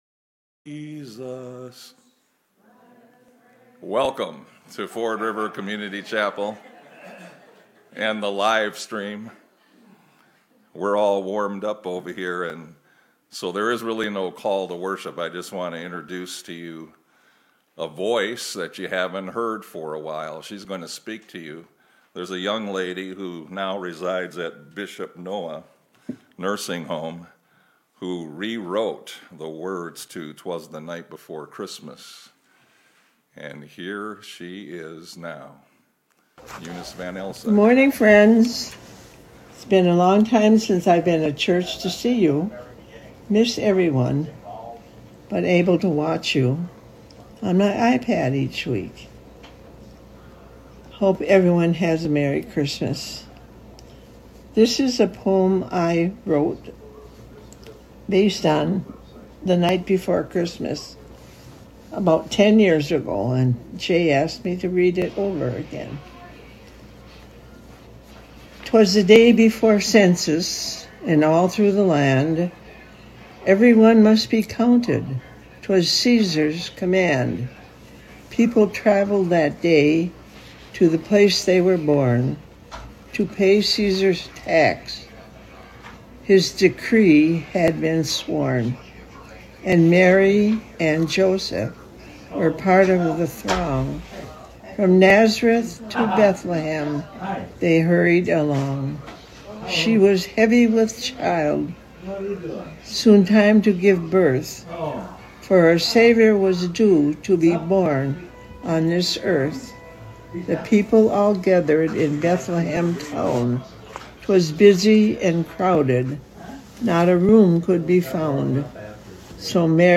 Christmas Program 2025